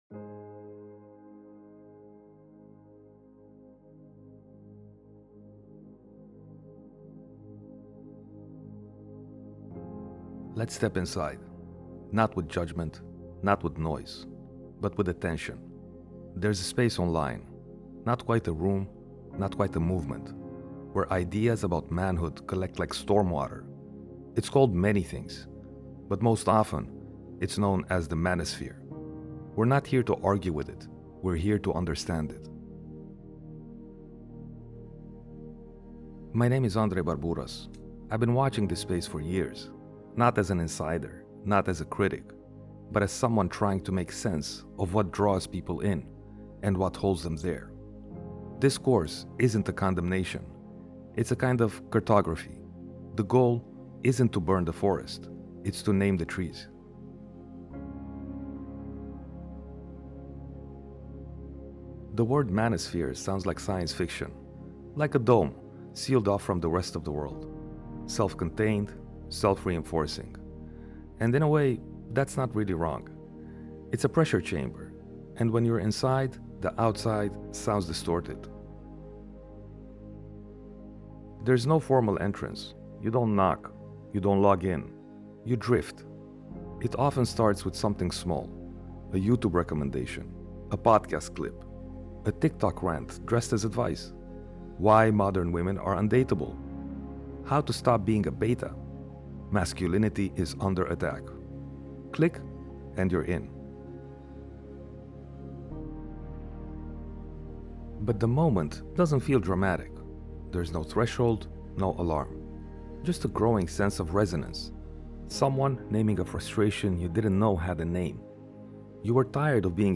This episode is voice-only.
No music-driven hype.